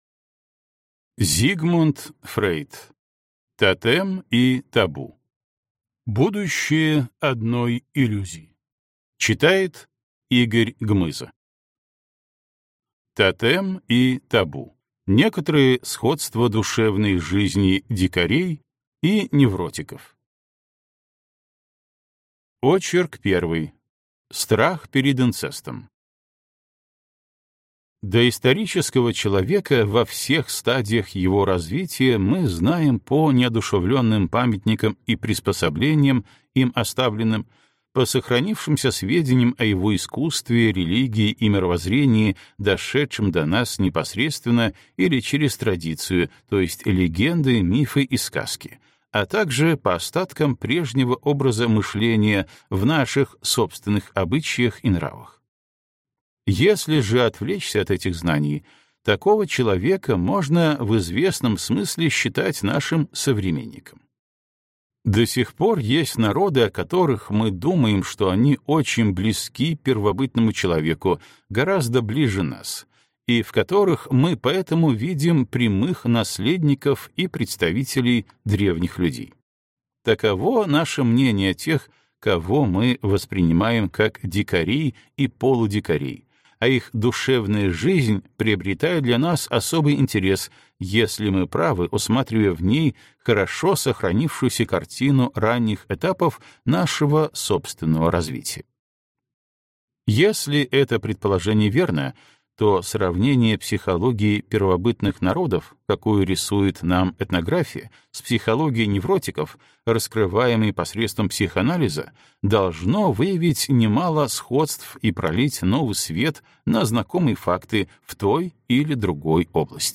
Аудиокнига Тотем и табу. Будущее одной иллюзии | Библиотека аудиокниг